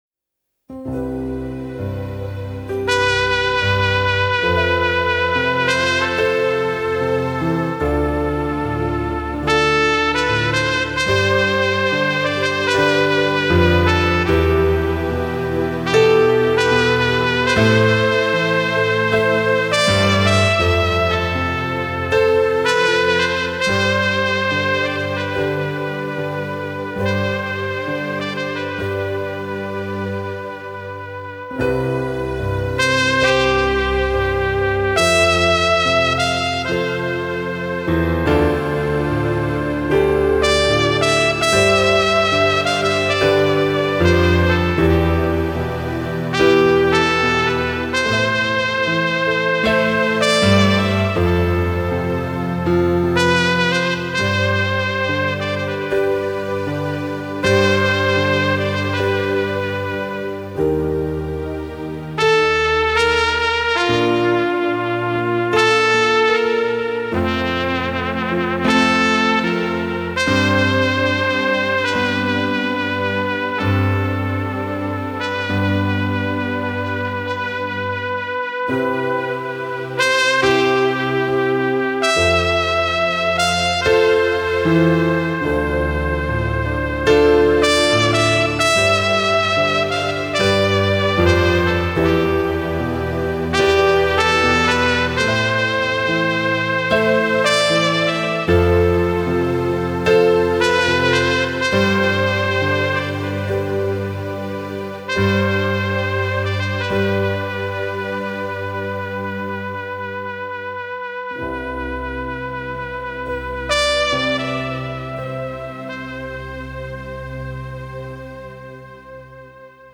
Søndag 28. november 2021:  NR 3 – TRUMPET JAM INSTRUMENTAL (Sang nr 88 – på 88 dager)
Hurra for mange fine instrumenter!